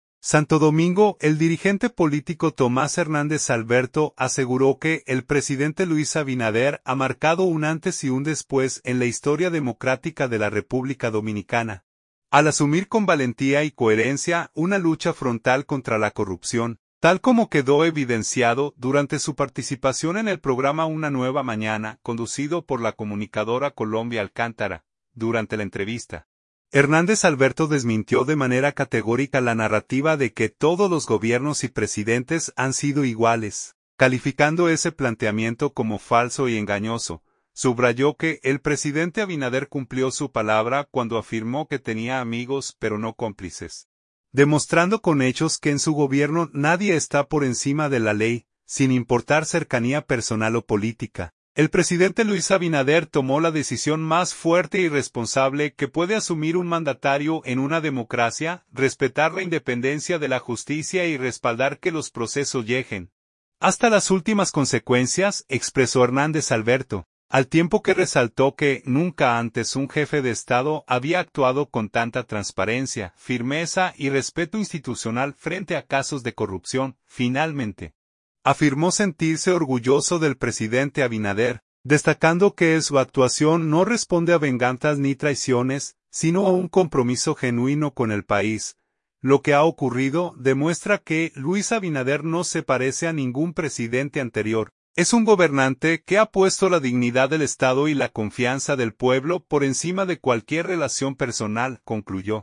Durante la entrevista